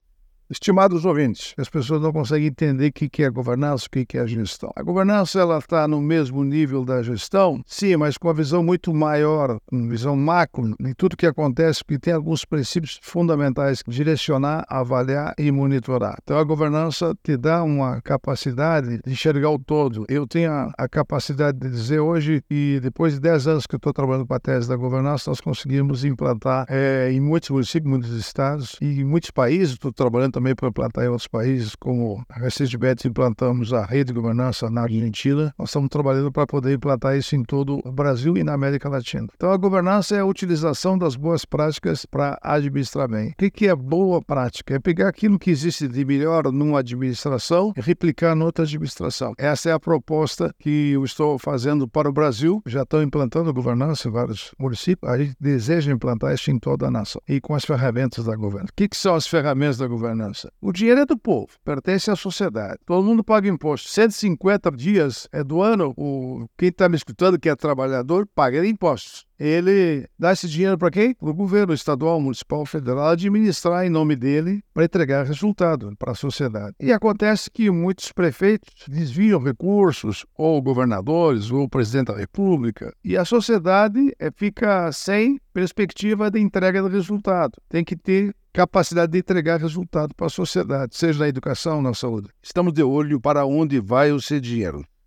É o assunto do comentário do ministro do Tribunal de Contas da União, Augusto Nardes, desta segunda-feira (10/06/24), especialmente para OgazeteirO.